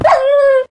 growl.ogg